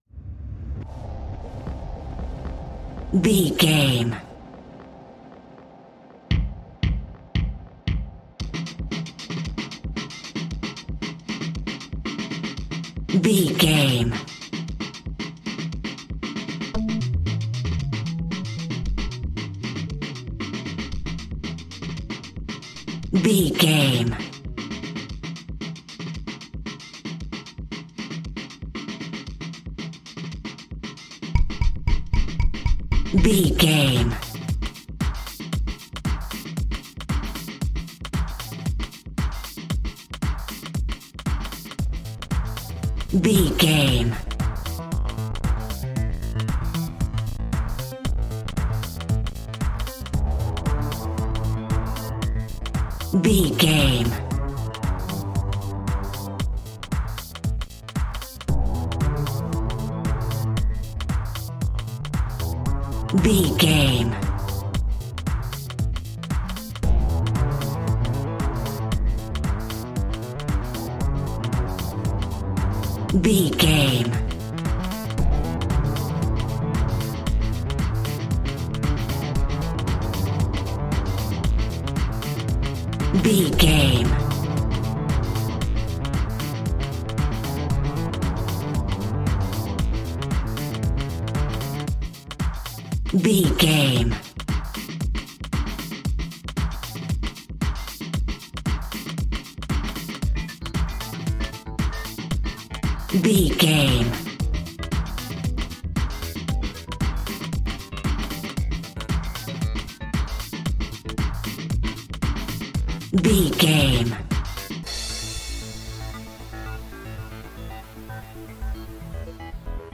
Electro House Uptempo.
Atonal
powerful
energetic
dark
hypnotic
synthesiser
drum machine
electric guitar
techno
synth lead
synth bass